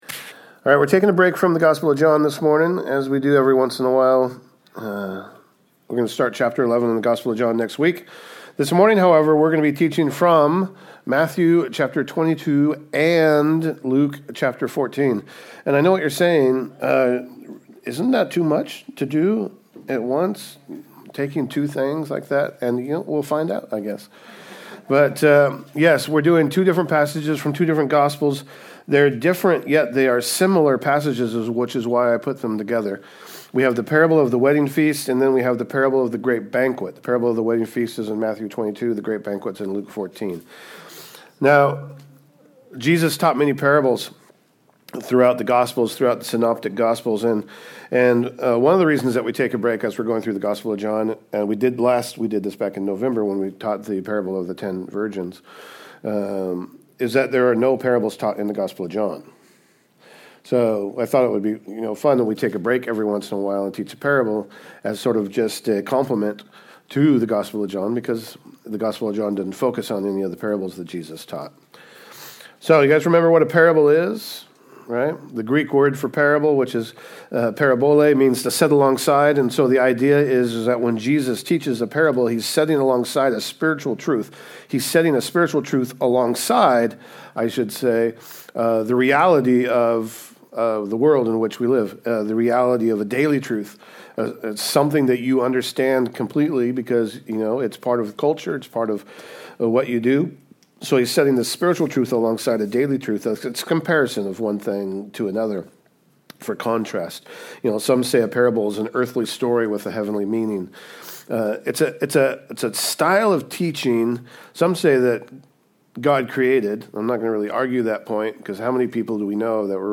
Sermons | Calvary Chapel Snohomish | Snohomish, Wa